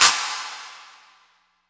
Clap OS 03.wav